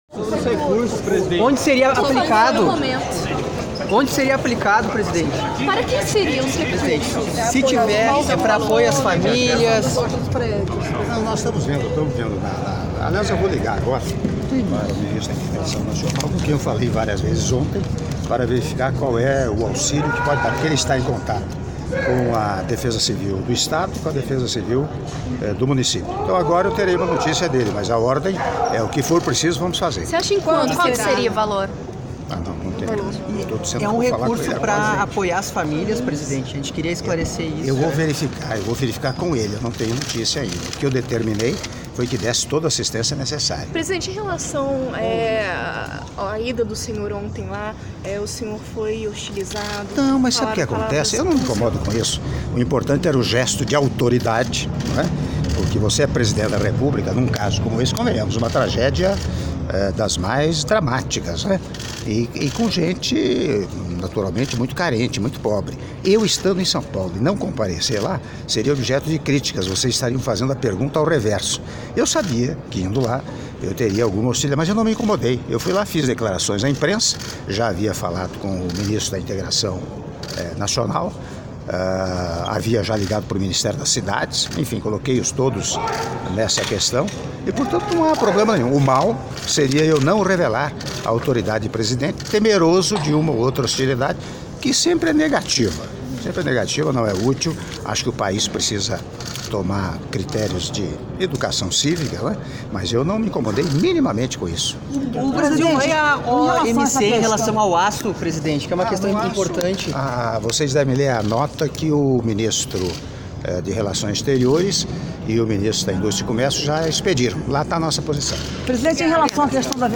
Áudio da entrevista coletiva concedida pelo Presidente da República, Michel Temer, após almoço em homenagem ao Senhor Desiré Delano Bouterse, Presidente da República do Suriname - Palácio Itamaraty (02min09s)